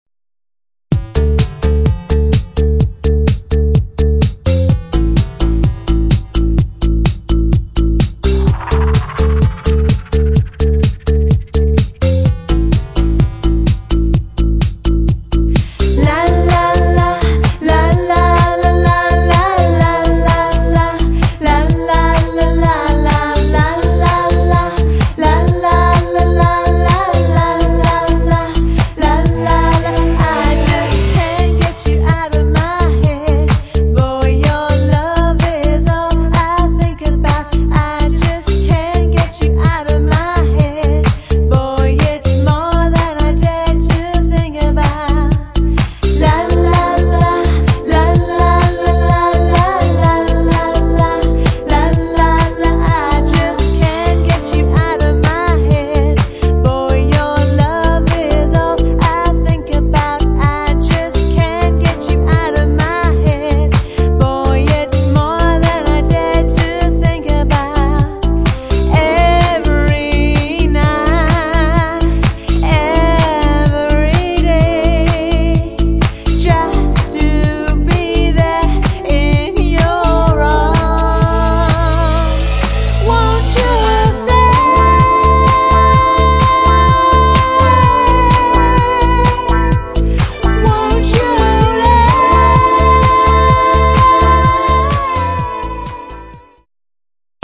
• Six-piece band
• Two female lead vocalists
• Exciting pop and covers band based in Cheltenham